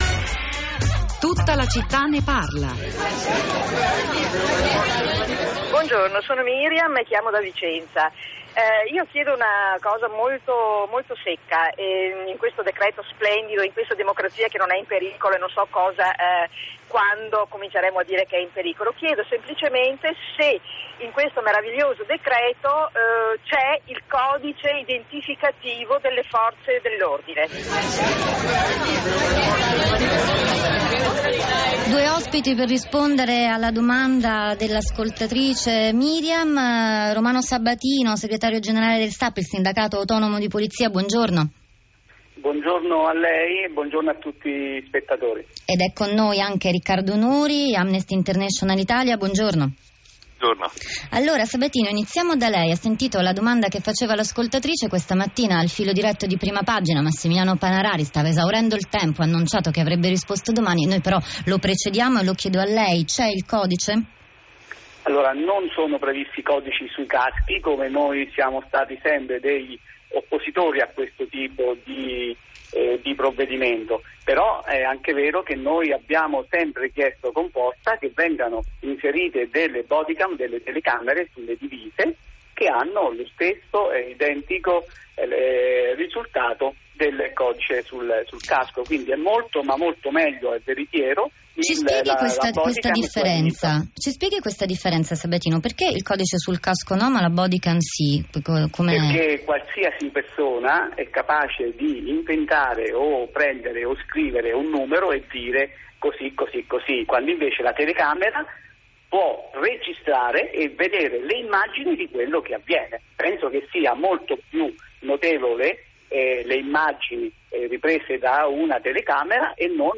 DL SICUREZZA BIS. CONFRONTO TRA SAP E AMNESTY SU RADIO RAI 3. NO IDENTIFICATIVI, SI BODYCAM